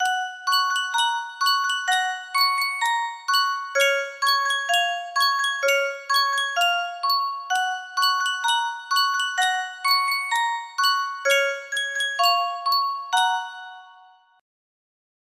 Sankyo Music Box - Ten Little Indians MG music box melody
Full range 60